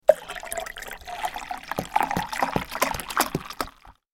دانلود آهنگ آب 6 از افکت صوتی طبیعت و محیط
دانلود صدای آب 6 از ساعد نیوز با لینک مستقیم و کیفیت بالا
جلوه های صوتی